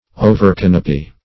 Overcanopy \O`ver*can"o*py\